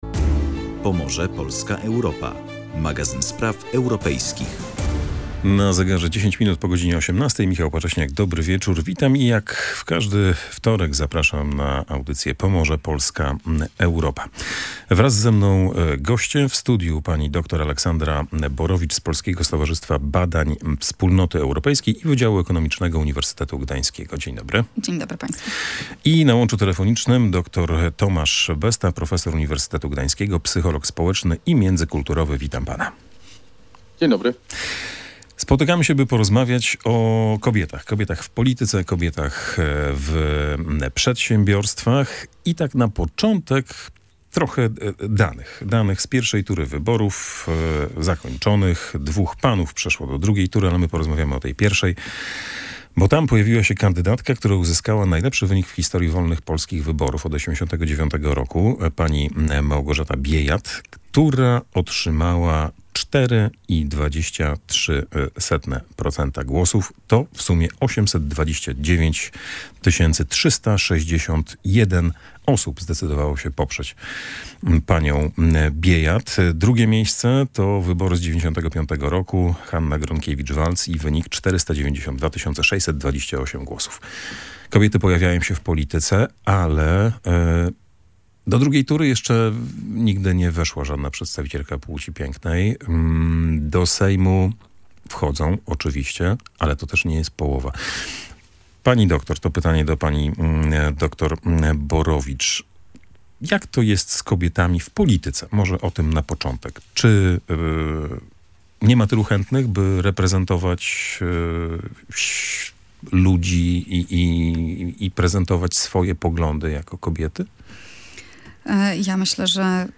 Na ten temat dyskutowali goście audycji „Pomorze, Polska, Europa”: